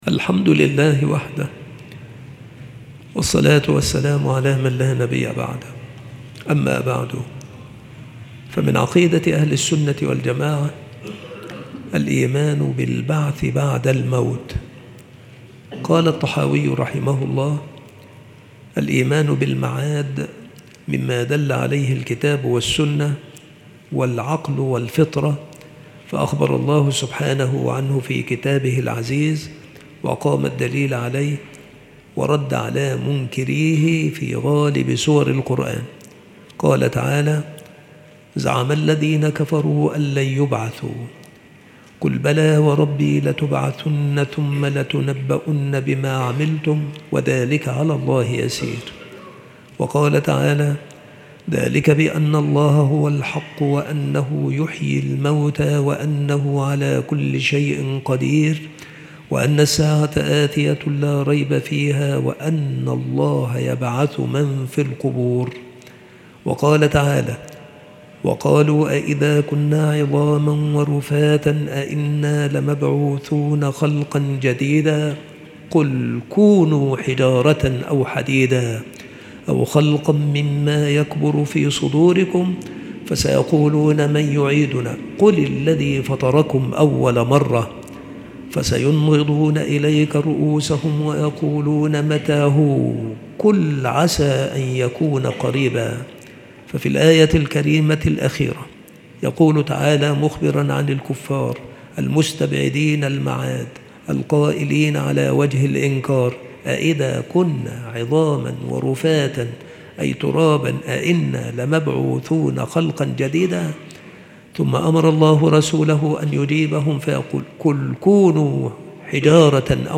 مواعظ وتذكير
مكان إلقاء هذه المحاضرة بالمسجد الشرقي - سبك الأحد - أشمون - محافظة المنوفية - مصر